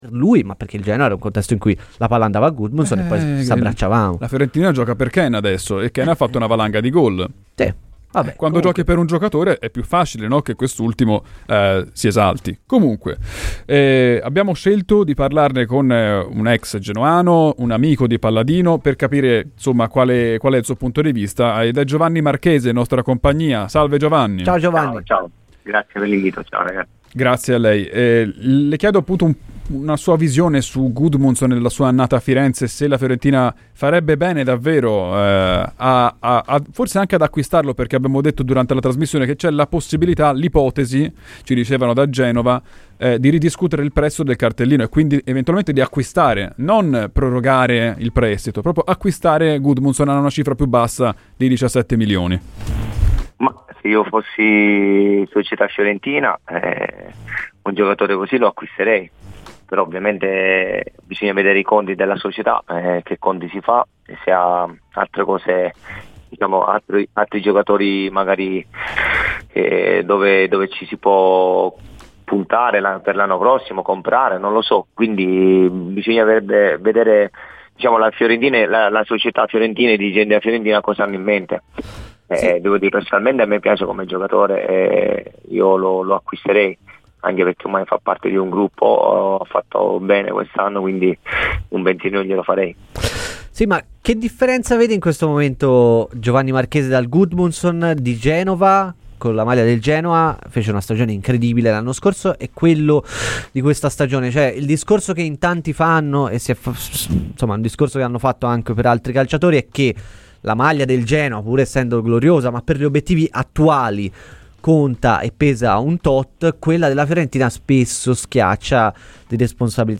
intervenuto a Radio FirenzeViola durante "I tempi supplementari" per parlare di attualità viola e in particolare di Gudmundsson.